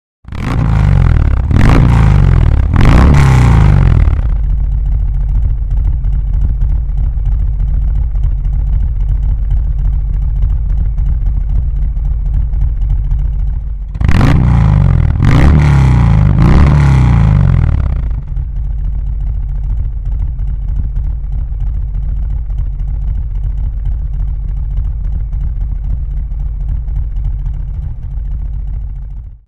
Audio senza Db Killer